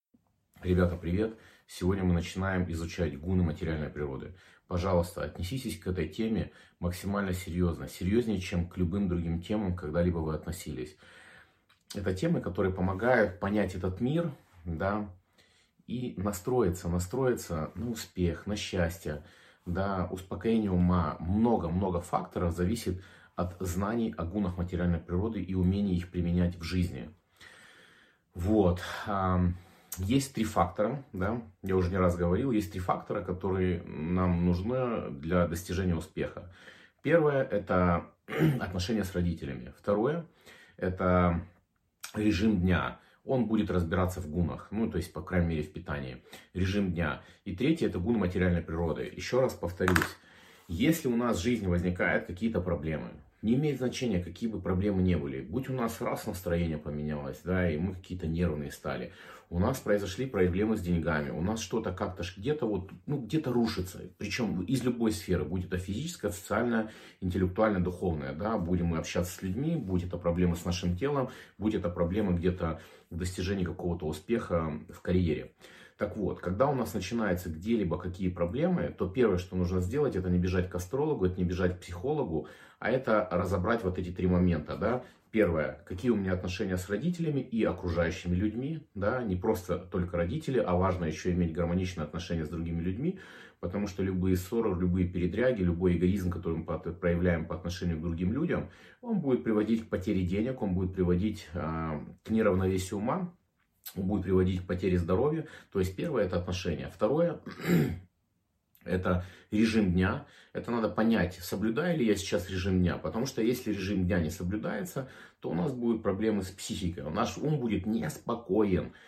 Аудиокнига Гуны материальной природы и перенос сознания | Библиотека аудиокниг